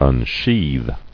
[un·sheathe]